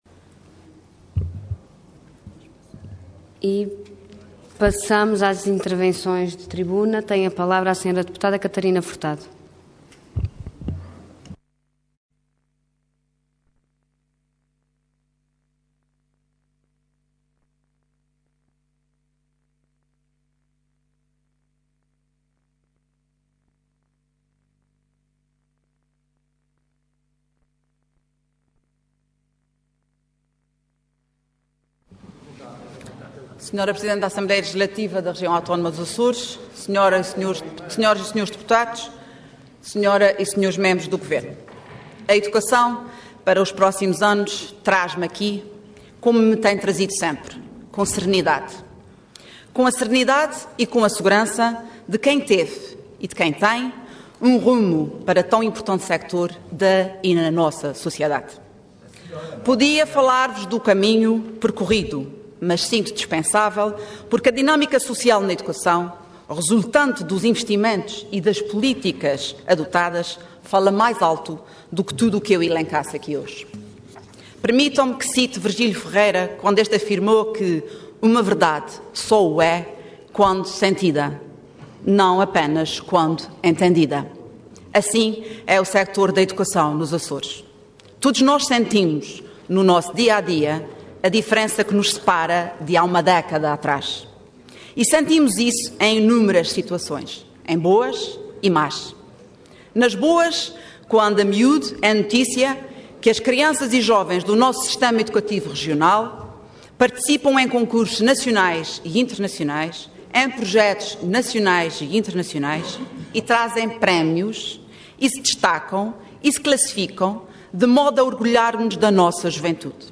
Website da Assembleia Legislativa da Região Autónoma dos Açores
Intervenção Intervenção de Tribuna Orador Catarina Moniz Furtado Cargo Deputada Entidade PS